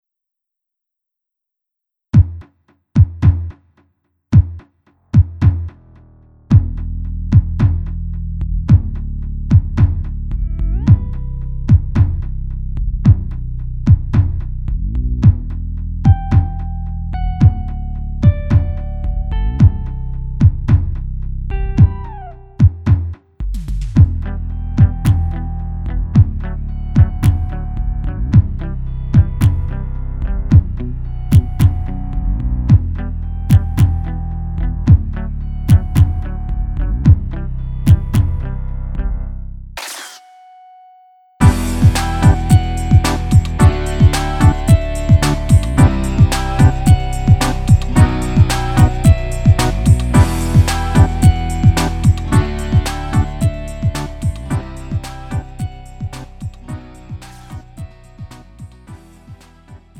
Lite MR은 저렴한 가격에 간단한 연습이나 취미용으로 활용할 수 있는 가벼운 반주입니다.
음정 원키
장르 가요